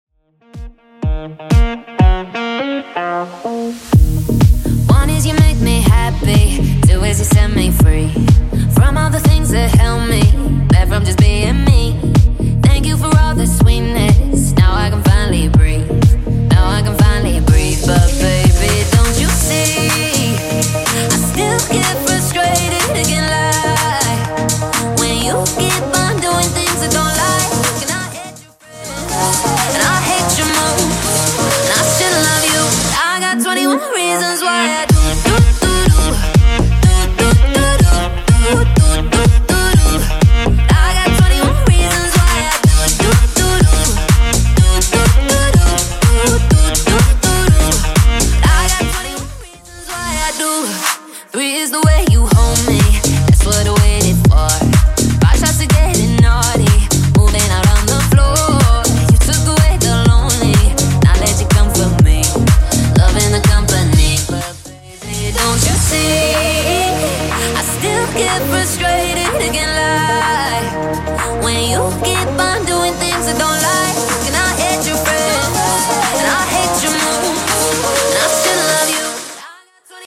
Genre: 90's Version: Clean BPM: 135